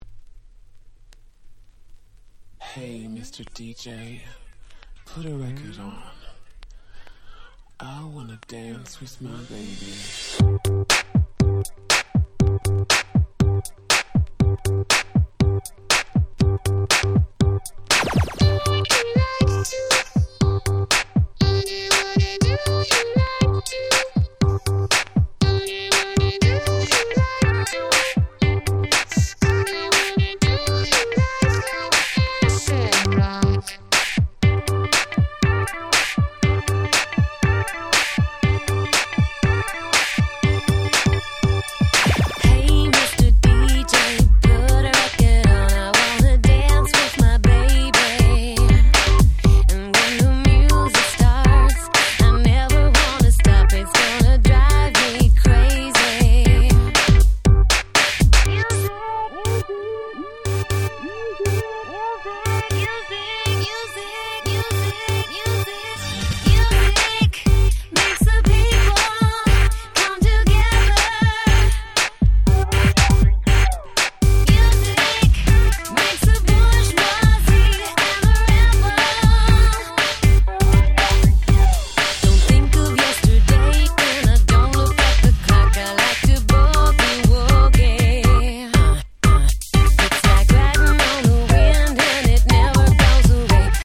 00' Super Hit Pops / R&B !!